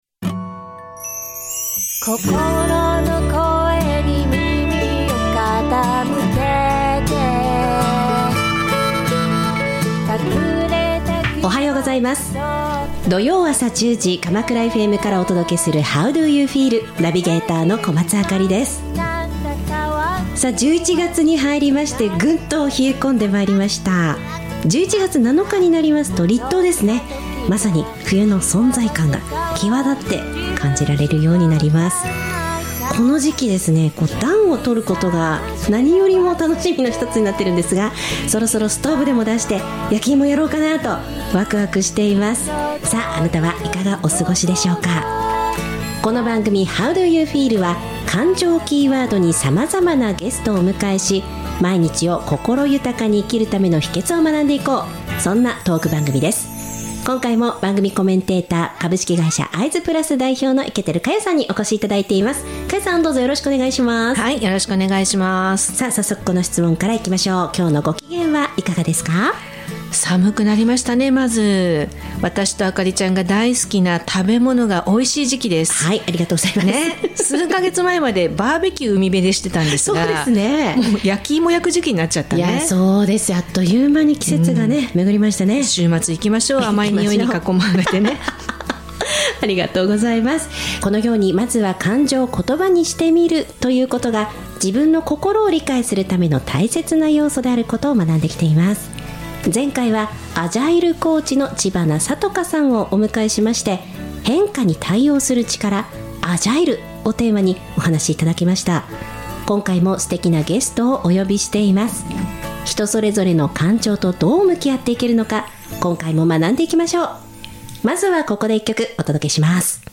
番組では「感情知性=EQ」に注目!自身の感情を見つめ、心を豊かにするヒントを学んでいく、ゲストトーク番組です。